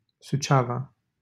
Suceava (Romanian: [suˈtʃe̯ava]